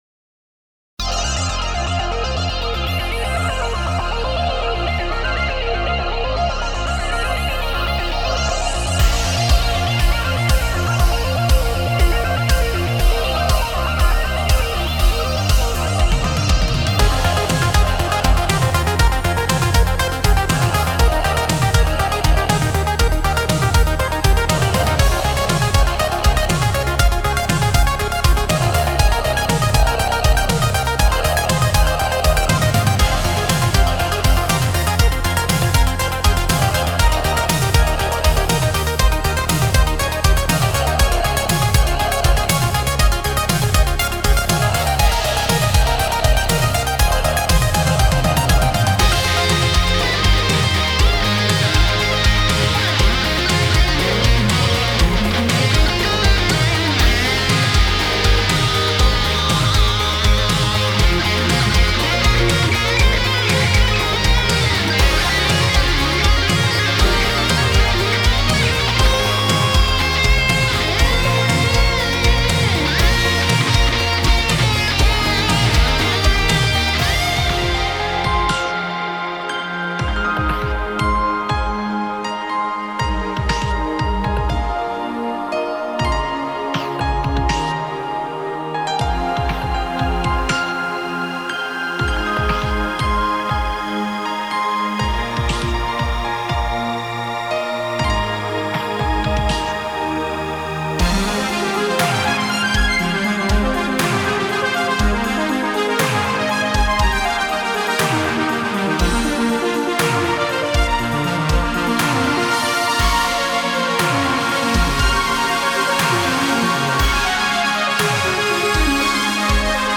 Genre: Synthwave.